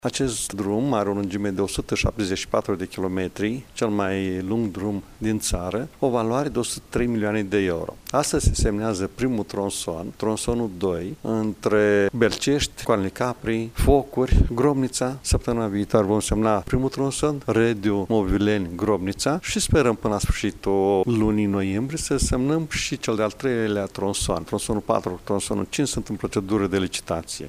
Axa Iaşi – Suceava măsoară aproximativ 170 de kilometri, iar proiectul a fost împărţit pe cinci tronsoane, după cum a declarat preşedintele Consiliului Judeţean Iaşi, Maricel Popa:
6-nov-rdj-12-Maricel-Popa-semnare-contract.mp3